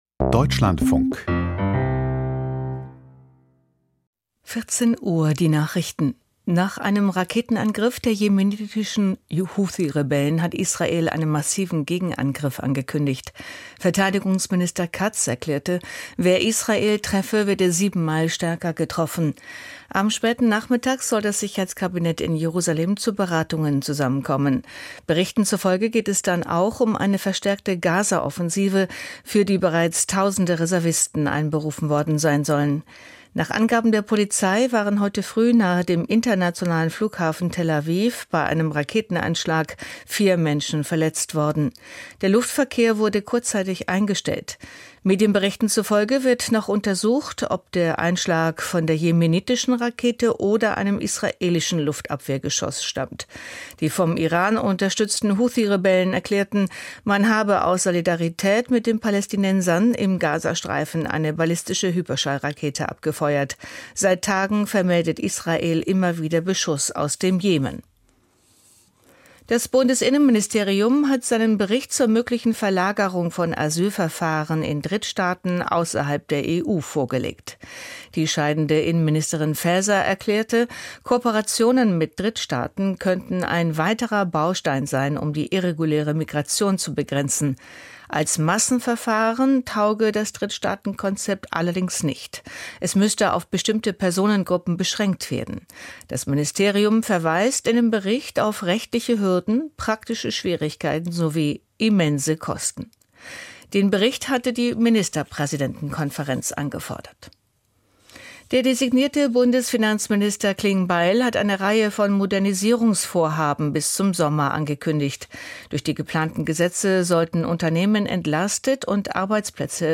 Die Deutschlandfunk-Nachrichten vom 04.05.2025, 14:00 Uhr